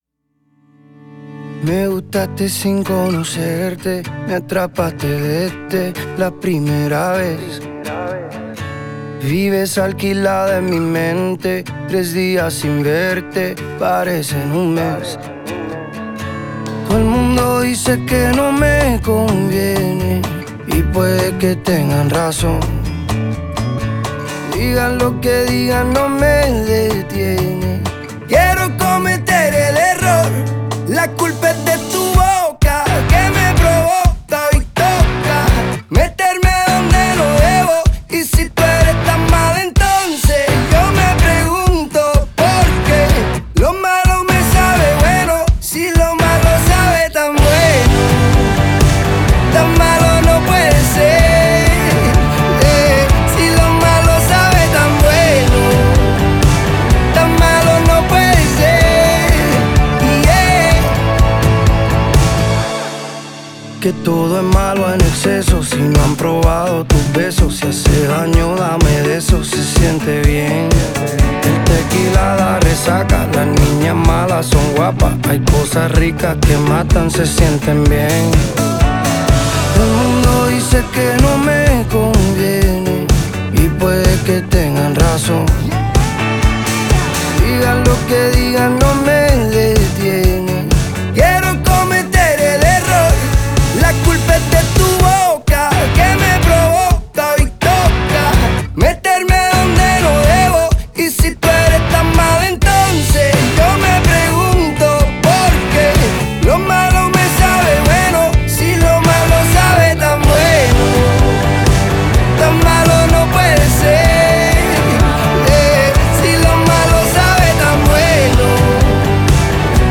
refrescante y vibrante es un pop rock